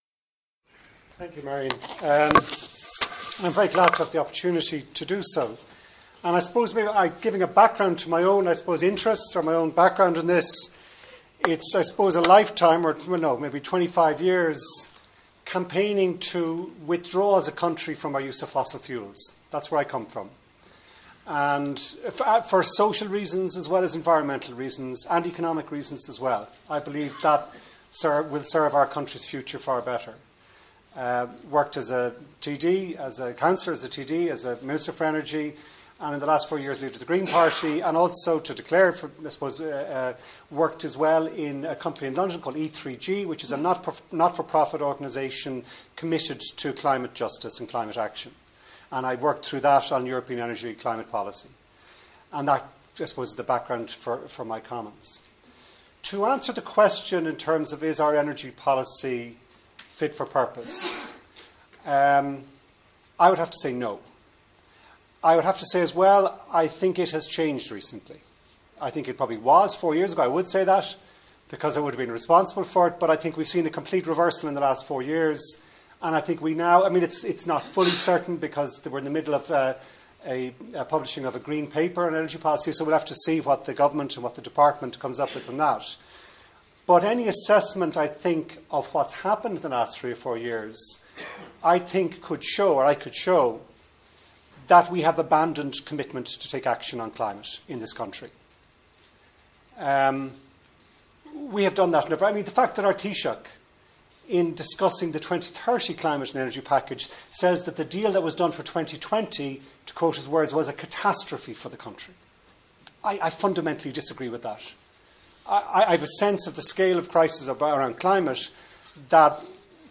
This is part one of Eamon Ryan's opening remarks at a debate on Irish energy policy, hosted by Marian Harkin MEP, in the Glenroyal Hotel, Maynooth, Friday December 5th 2014.